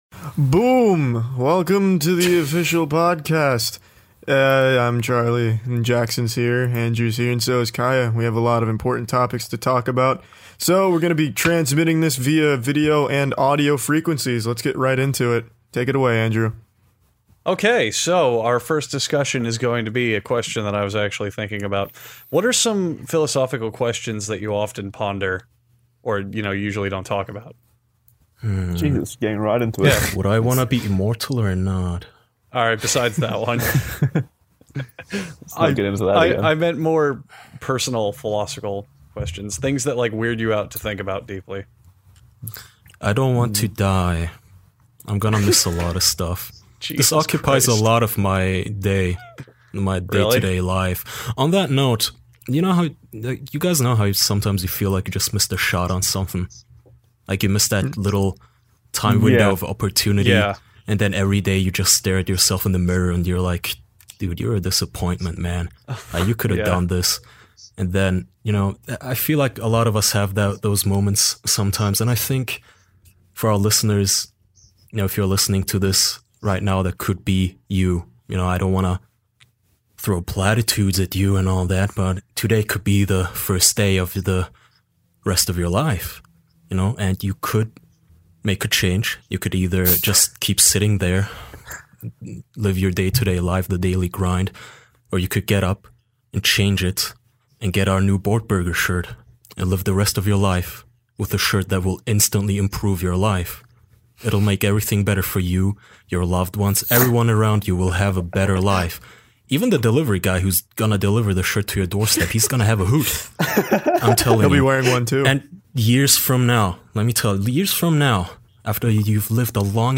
Four close man friends gather around to discuss theatre.